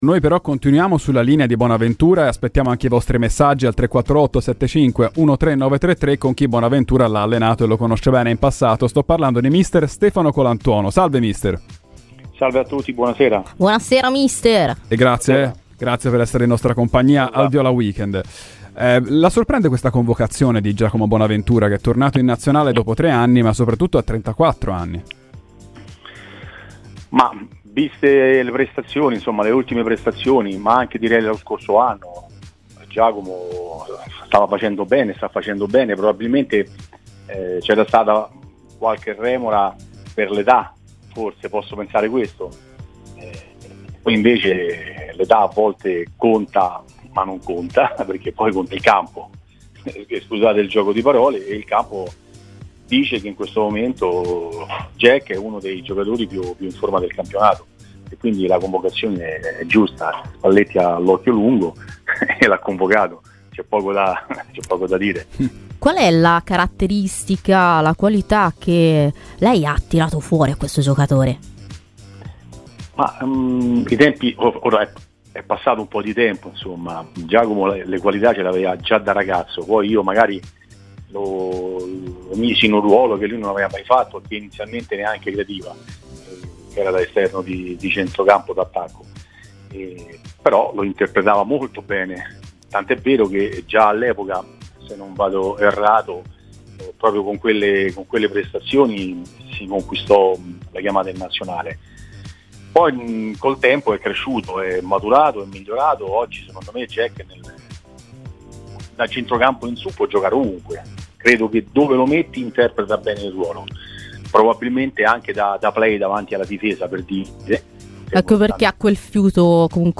L'allenatore Stefano Colantuono, ha parlato oggi a Radio Firenzeviola, durante 'Viola Weekend'.